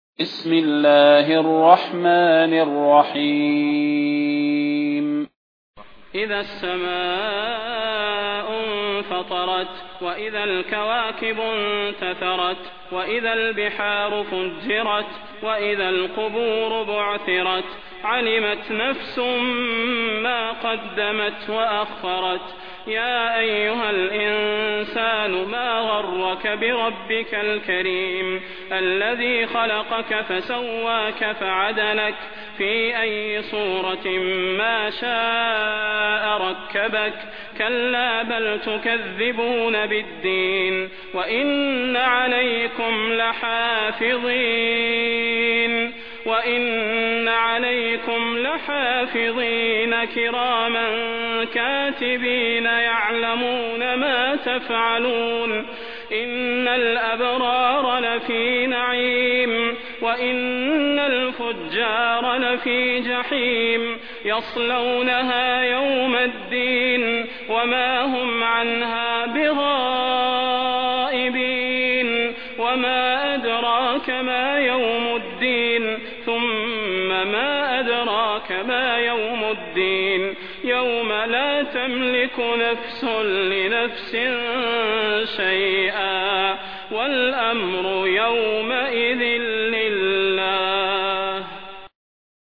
المكان: المسجد النبوي الشيخ: فضيلة الشيخ د. صلاح بن محمد البدير فضيلة الشيخ د. صلاح بن محمد البدير الانفطار